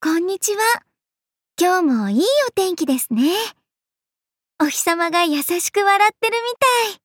小鸟游雏田普通登录语音.mp3